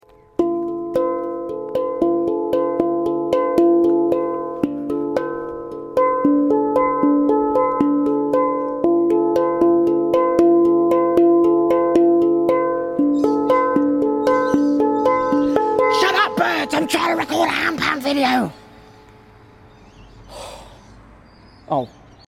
The handpan guy who’s always